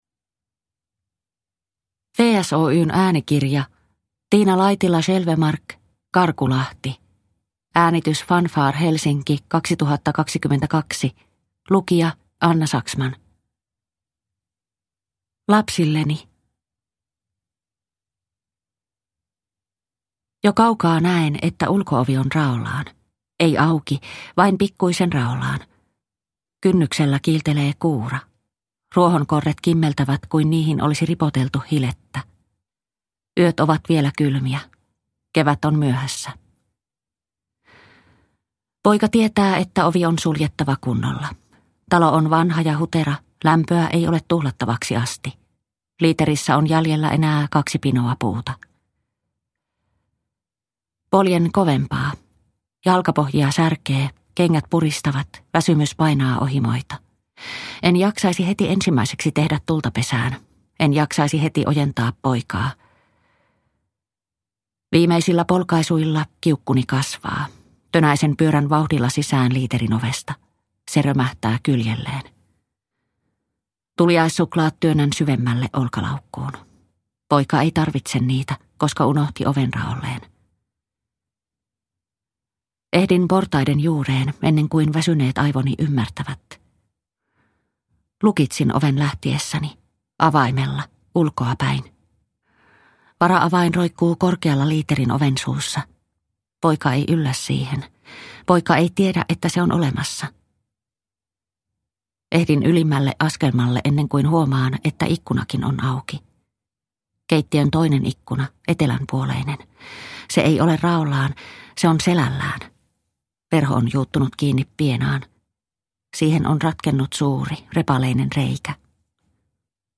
Karkulahti – Ljudbok – Laddas ner